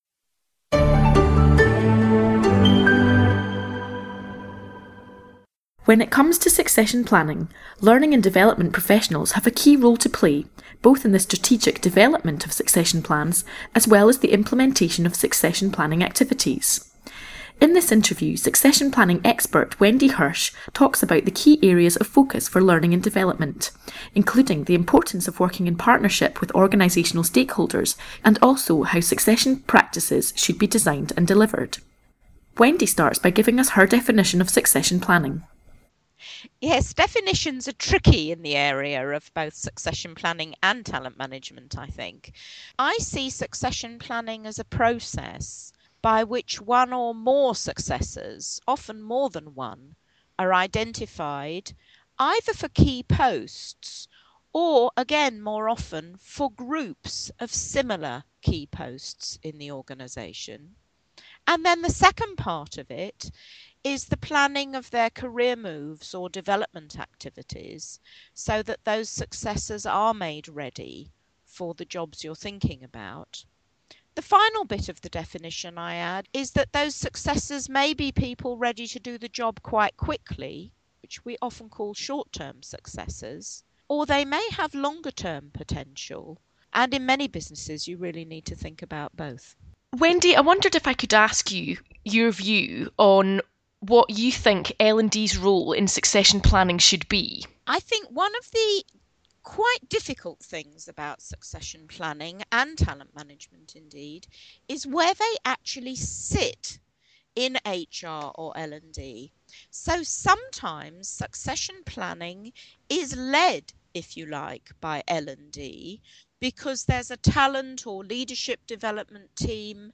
podcast Succession planning Talent, succession and careers Succession Planning podcast for L&D professionals Related Publications Document Succession Planning podcast (an overview) Document Succession Planning podcast (full interview)